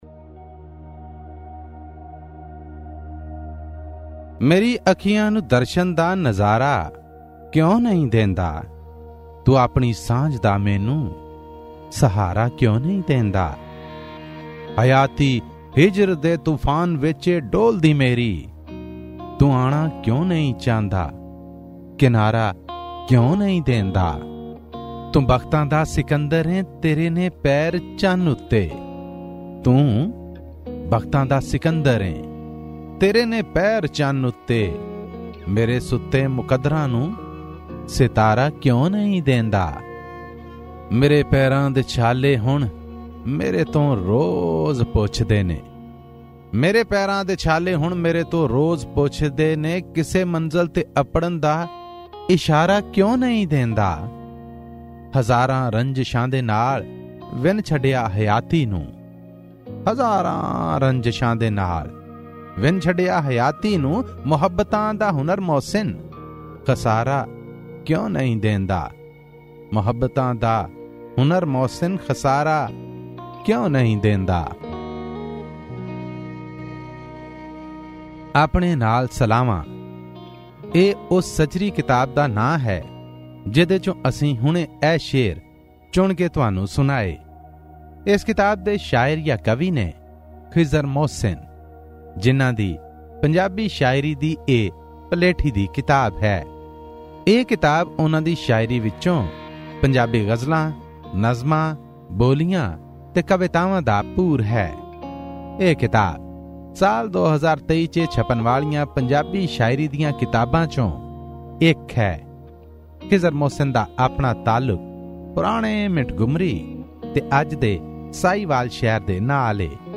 Pakistani Punjabi poetry book review